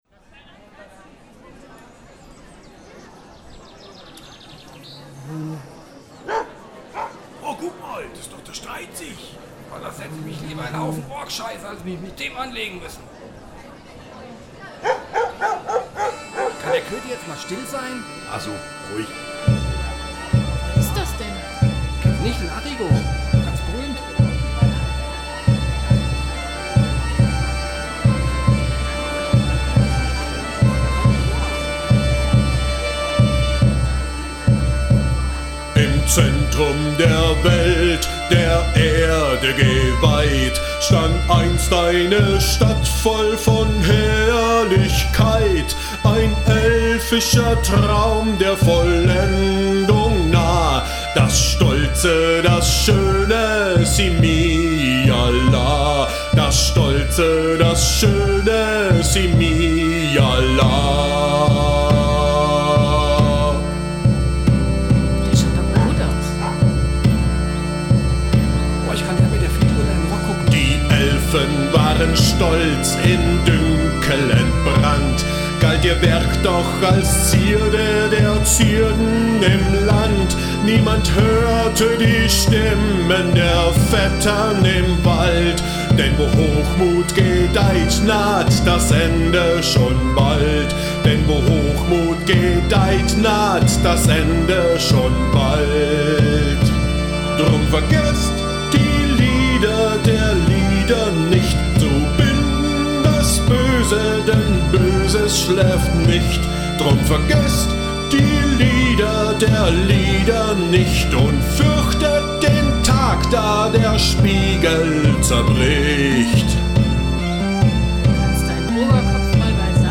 musikalische und atmosphärische Untermalung für den Besuch der Helden im Gauklerlager auf Burg Falkenwind
Neben der von Arrigo vorgetragenen Weise vom Basilisken füllen auch hörspielartige Elemente das Gauklerlager mit Leben.